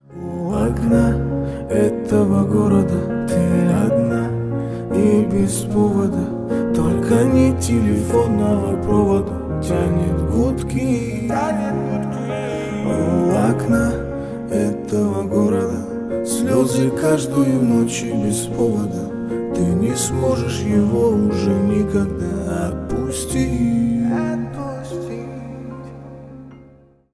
Поп Музыка
грустные # спокойные # кавер